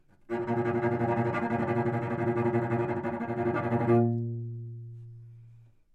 双重低音单音 " 双重低音 A2
描述：在巴塞罗那Universitat Pompeu Fabra音乐技术集团的goodsounds.org项目的背景下录制。单音乐器声音的Goodsound数据集。 乐器::双重bassnote :: A＃octave :: 2midi音符:: 46microphone :: neumann U87调音参考:: 442.0goodsoundsid :: 8611
标签： 好声音 单注 多样本 Asharp2 neumann- U87 低音提琴
声道立体声